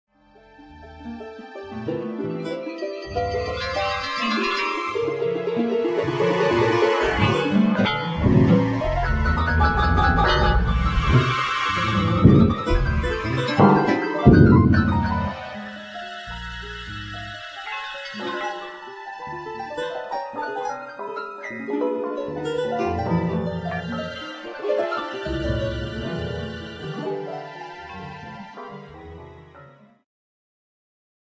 ジャンル アンビエント
癒し系